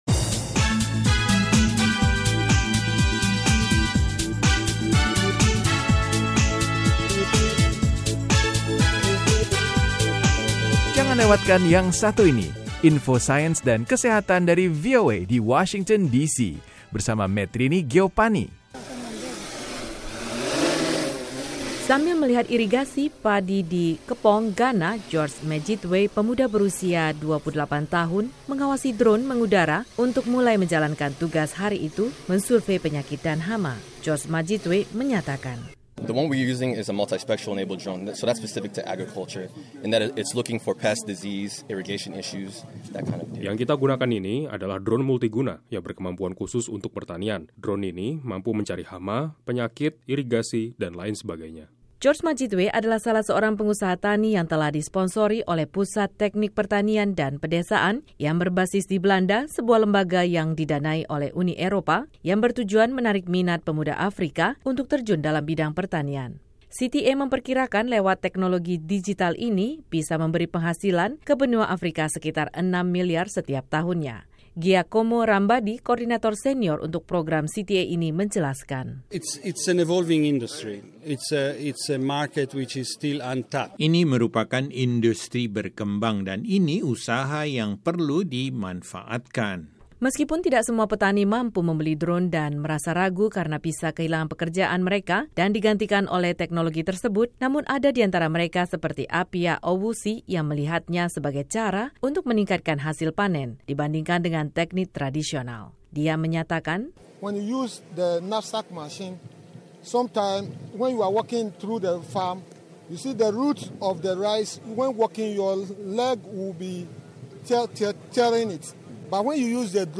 melaporkanya dari Accra.